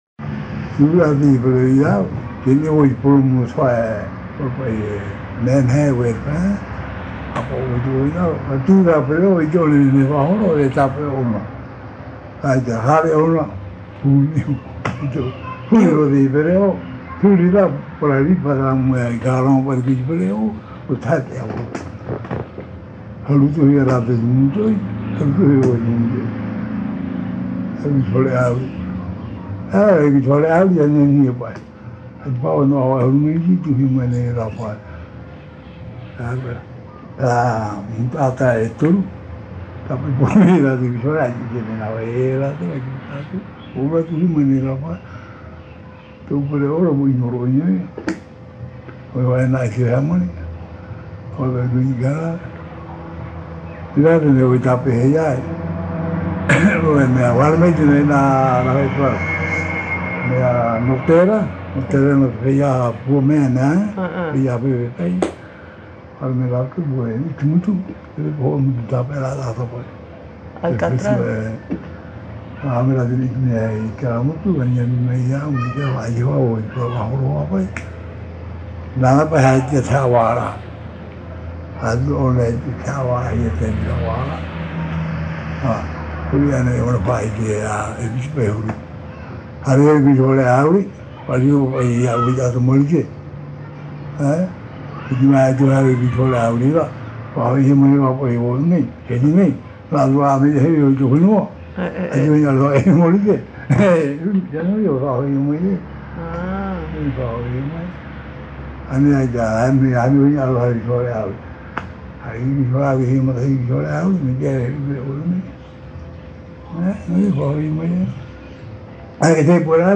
Interview réalisée à Pape'ete sur l’île de Tahiti.
Papa mātāmua / Support original : cassette audio